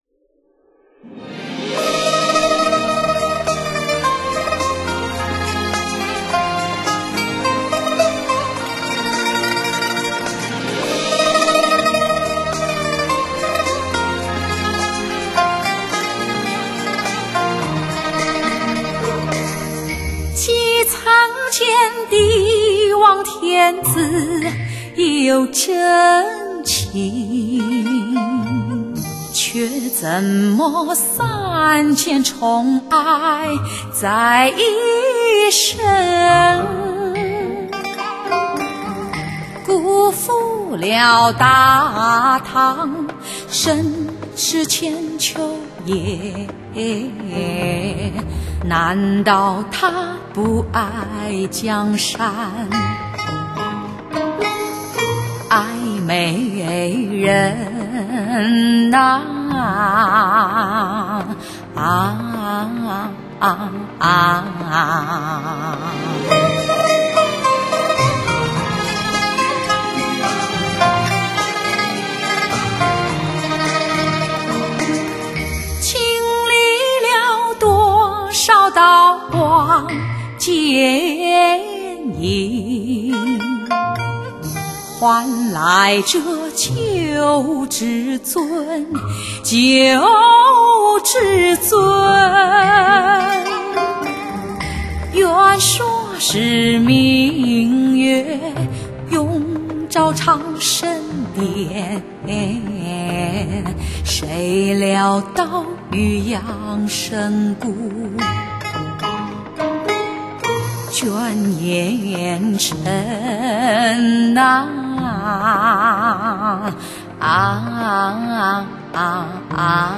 用真情解读着每首歌曲本身所持有的内涵和魅力。
演绎着古代帝王般的豪气。
（试听曲为低品质wma，下载为320k/mp3）